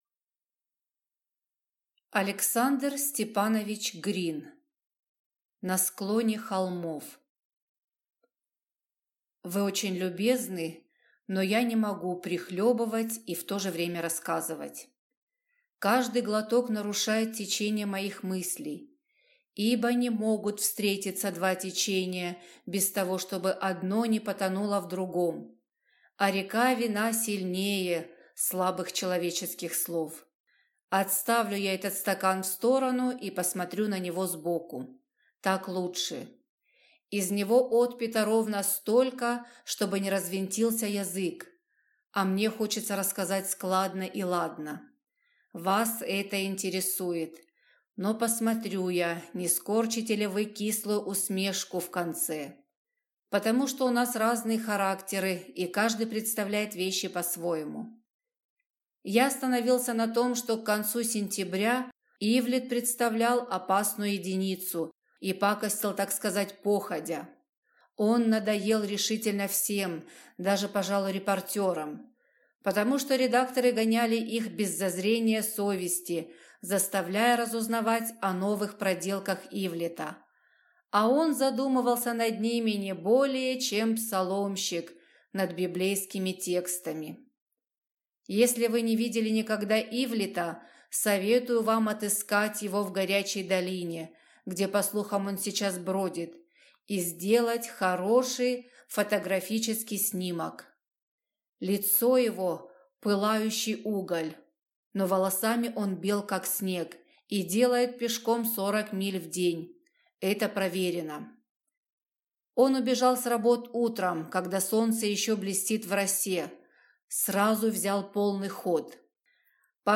Аудиокнига На склоне холмов | Библиотека аудиокниг